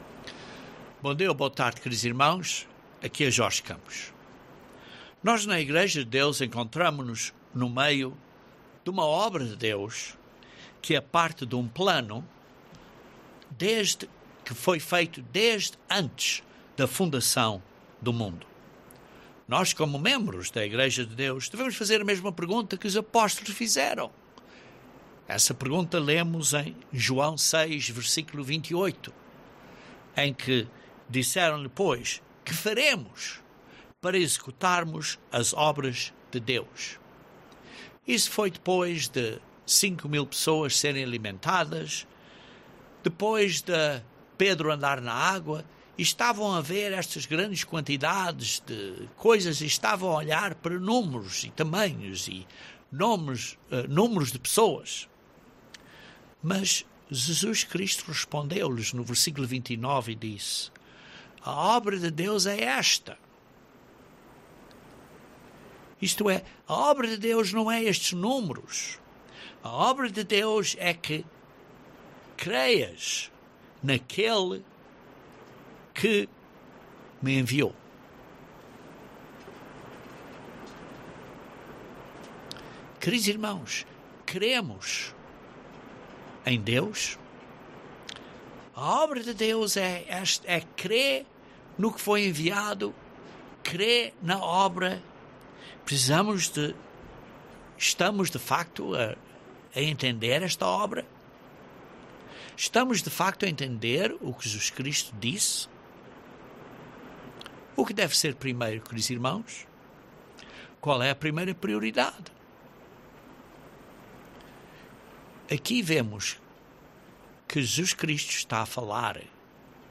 Este sermão explica este acordo de amor que o Pai e o Filho têm para criar mais filhos na Família Deles.